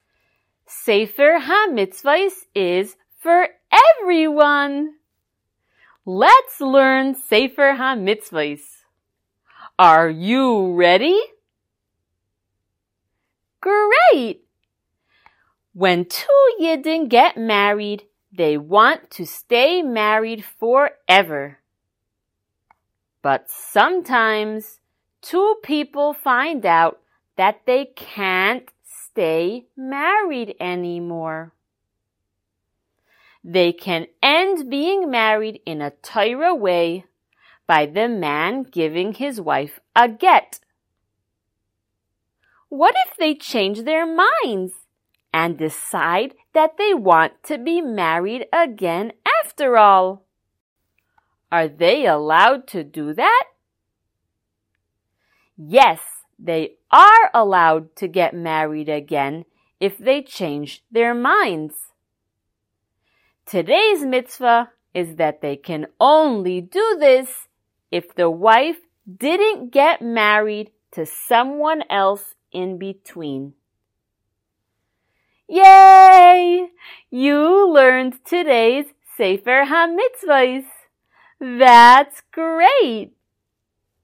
Color Shiur #80!
SmallChildren_Shiur080.mp3